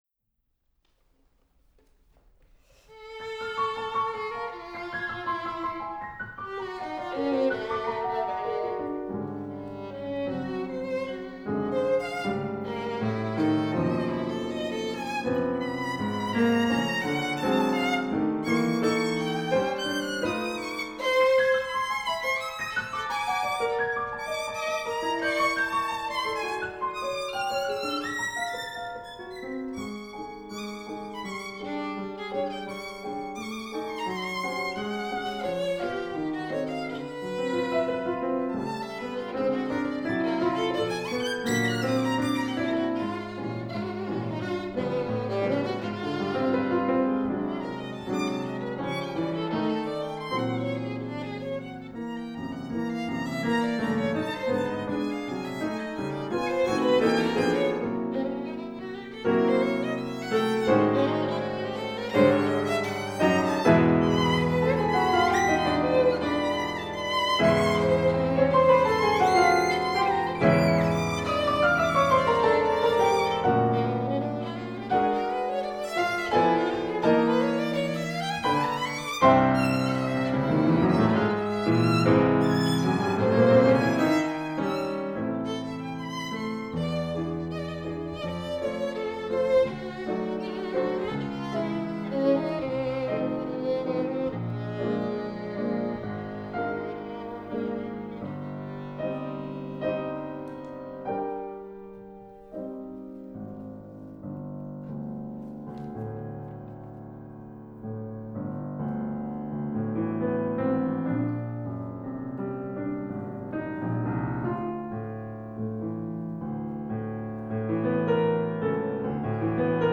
piano
With strength and expression